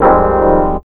CHORD06 01-R.wav